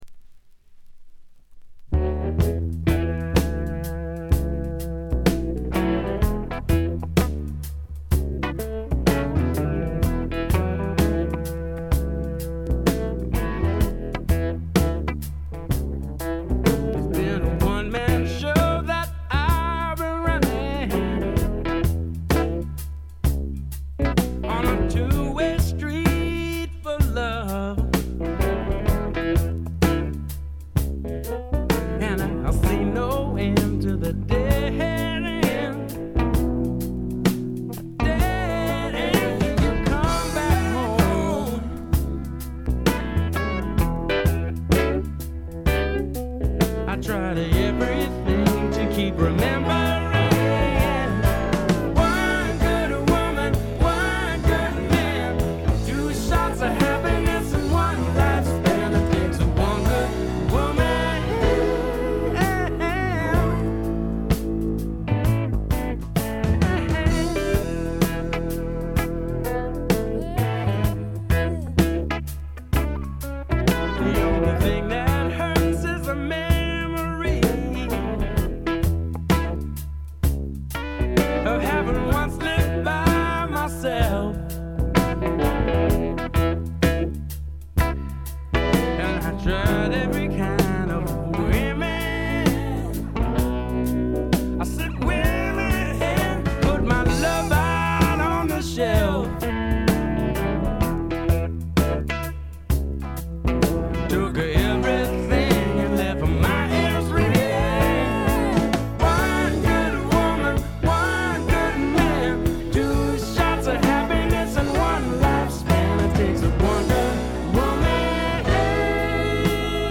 keyboards, synthesizer, vocals